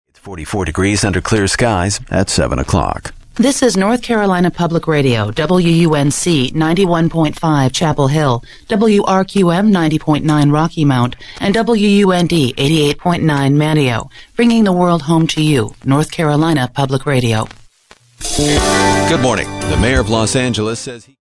WUND-FM Top of the Hour Audio: